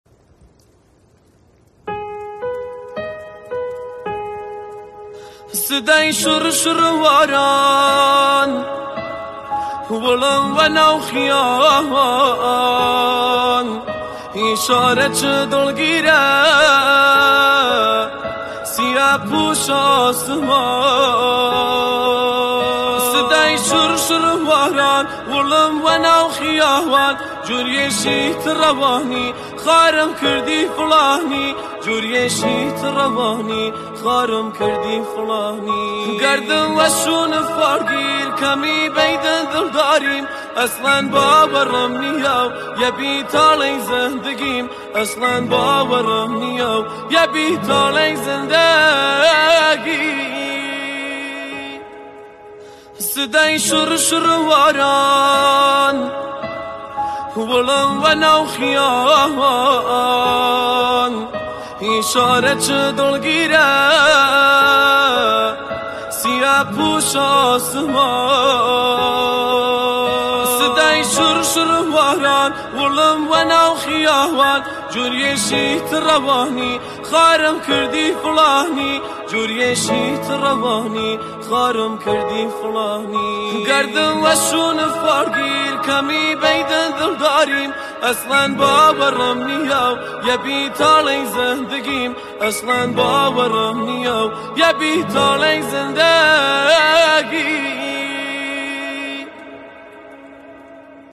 جدیدترین آهنگ عاشقانه و بارانی